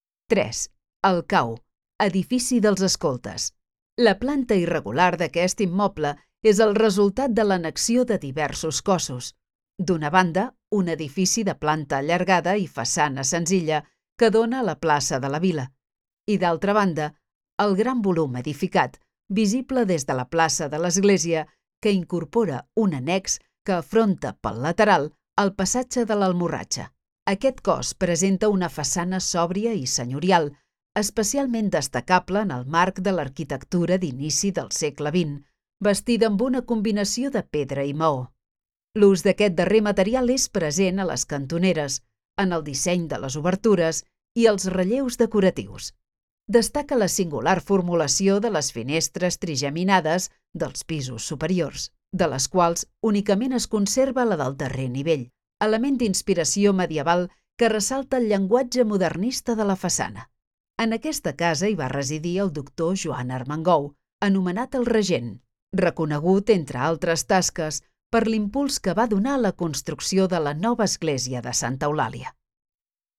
Audioguia